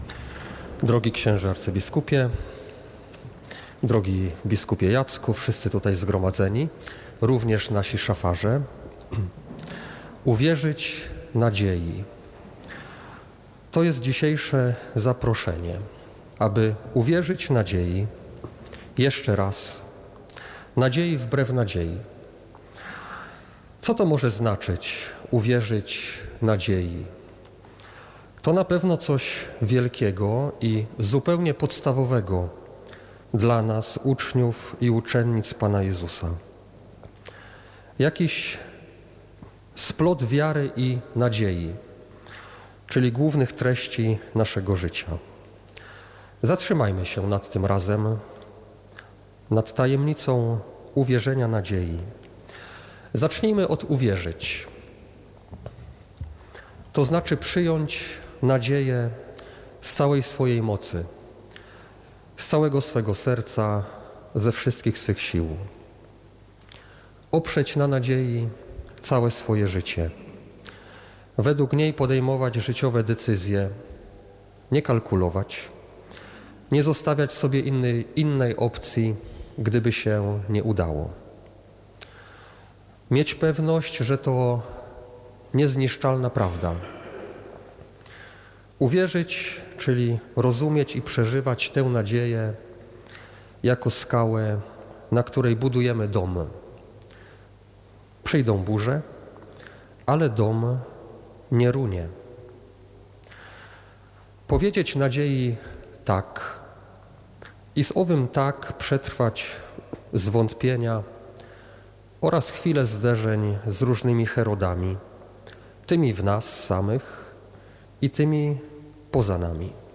homilia-sobotnia.mp3